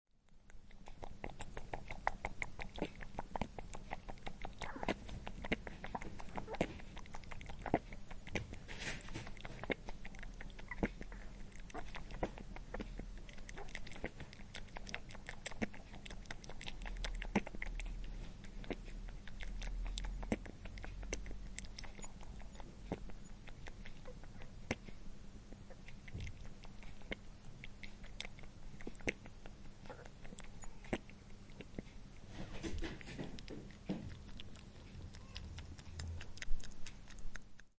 Rabbit Drinking Bouton sonore
Animal Sounds Soundboard338 views